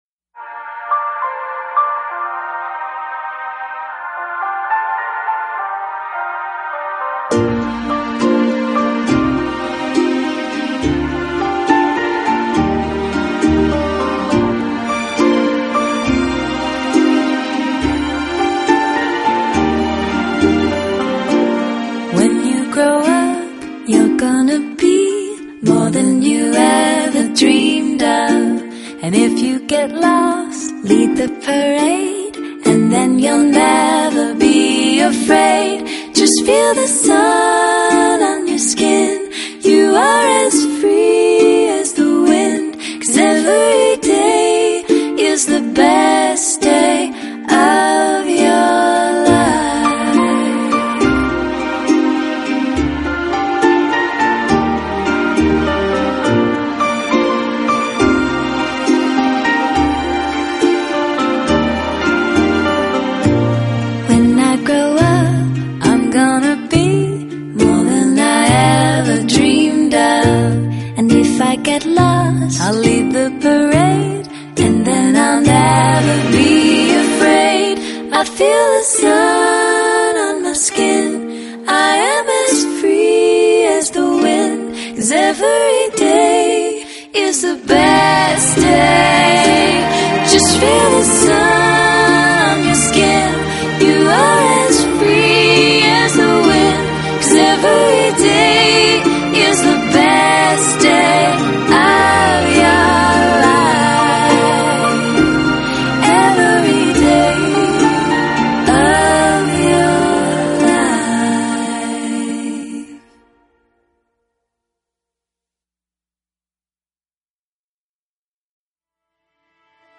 加拿大女聲二重唱
清爽凜冽的女聲二重唱